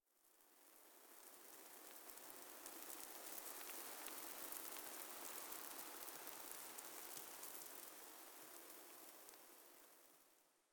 Minecraft Version Minecraft Version snapshot Latest Release | Latest Snapshot snapshot / assets / minecraft / sounds / ambient / nether / basalt_deltas / debris2.ogg Compare With Compare With Latest Release | Latest Snapshot
debris2.ogg